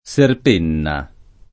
vai all'elenco alfabetico delle voci ingrandisci il carattere 100% rimpicciolisci il carattere stampa invia tramite posta elettronica codividi su Facebook Scerpena [ + šerp % na ] o Scerpenna [ + šerp % nna ] (antiq. Serpenna [ S erp % nna ]) top. (Tosc.)